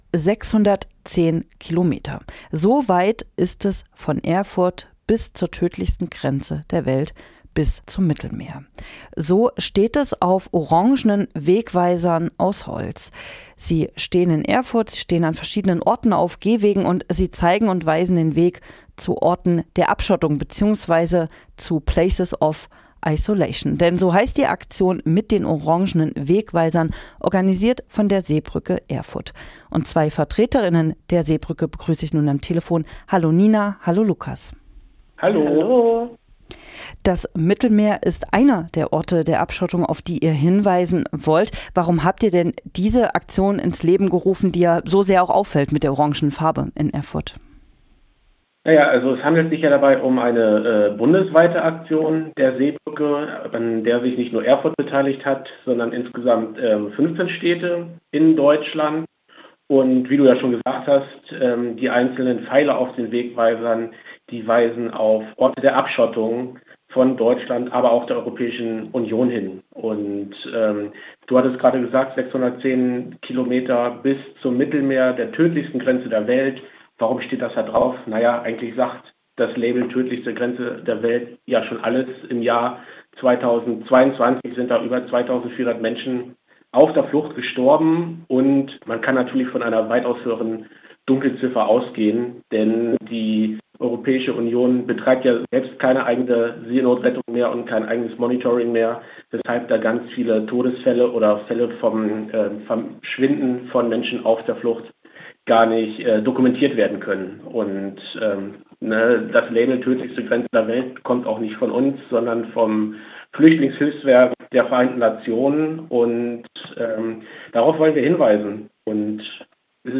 Wegweiser "Orte der Abschottung" | Interview mit der Seebrücke Erfurt